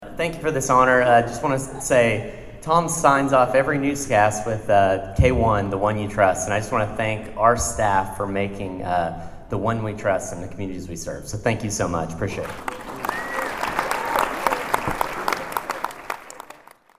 It was a huge night Thursday for your Bartlesville Radio stations at the Oklahoma Association of Broadcasters Awards Ceremony in Tulsa.